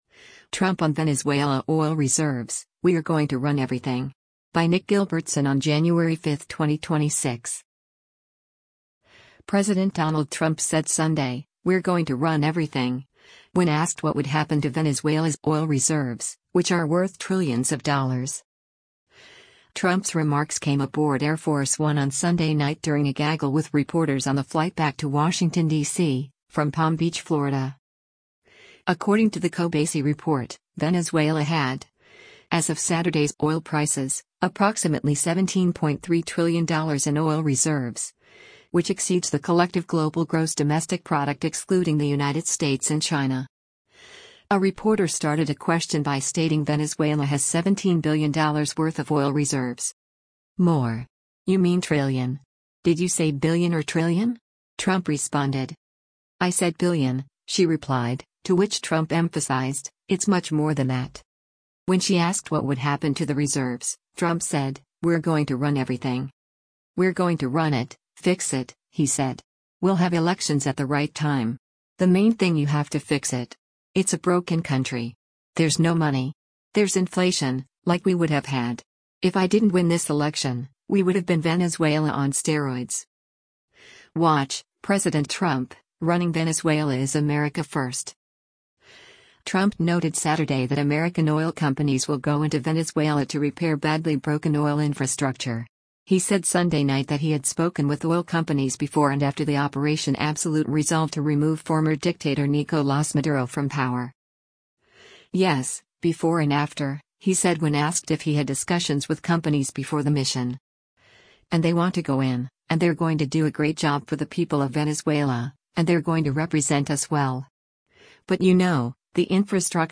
Trump’s remarks came aboard Air Force One on Sunday night during a gaggle with reporters on the flight back to Washington, DC, from Palm Beach, Florida.